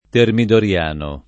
[ termidor L# no ]